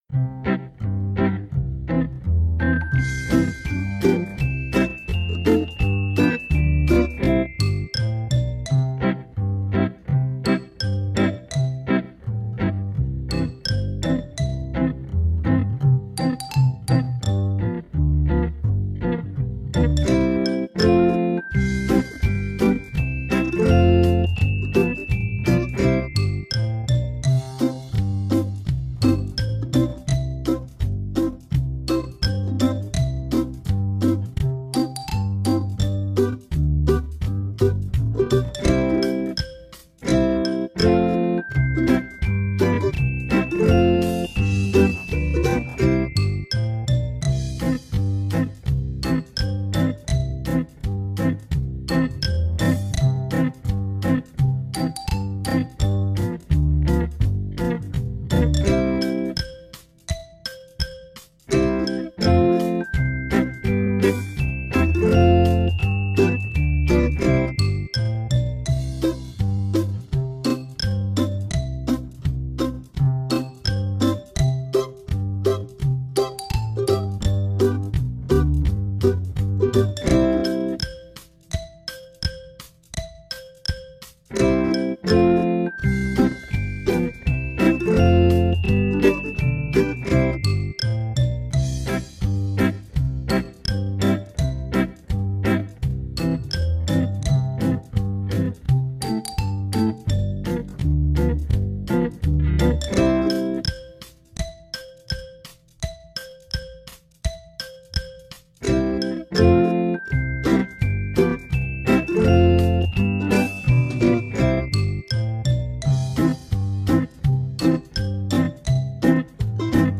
The Green Grass Grows All Around slower.mp3